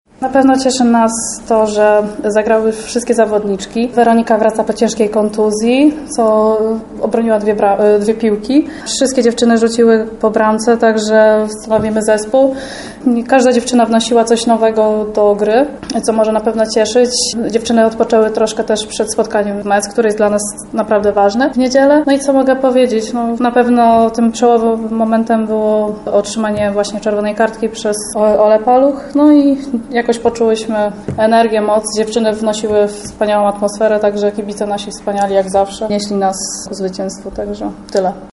Oto co powiedziały bohaterki meczu podczas konferencji prasowej po jego zakończeniu: